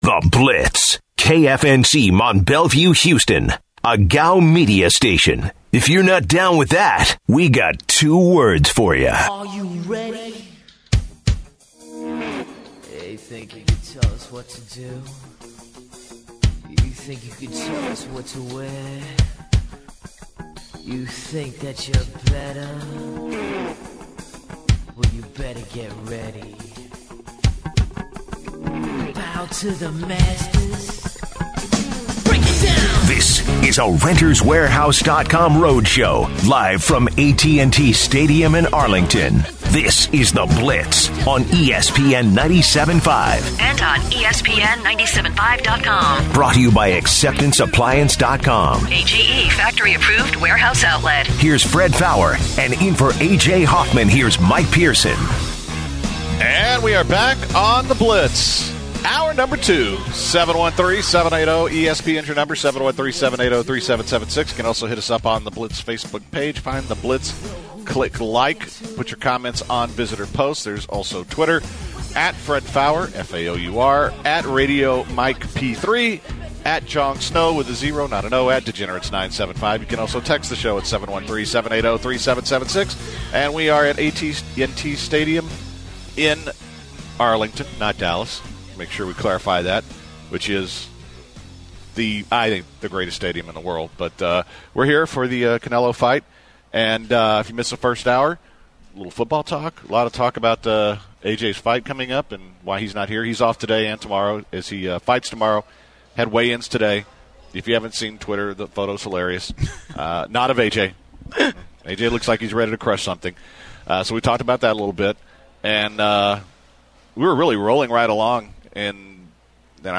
This hour on the Blitz the guys discussed the NFL Games coming up in week 2. They are also taking twitter and phone comment and discussing the University of Houston game tonight.